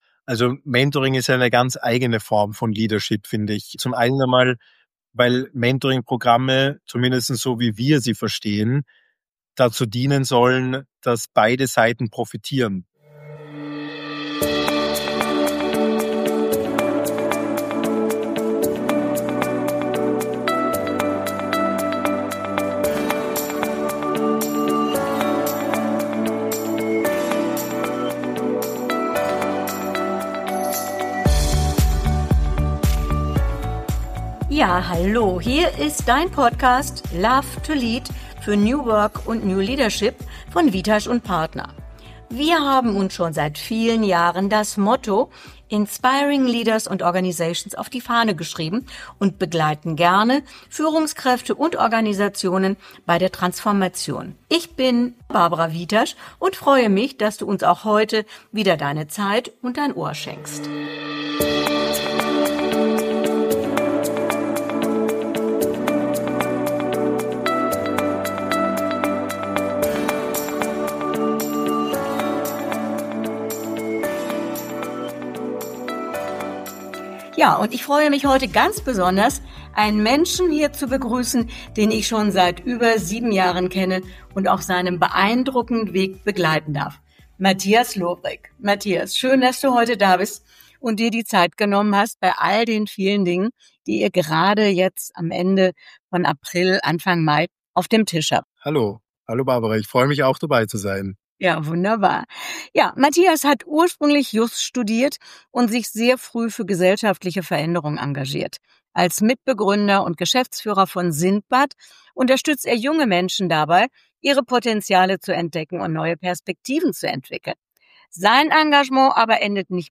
Ein inspirierendes Gespräch über Führung, Verantwortung und die Kraft von Beziehungen.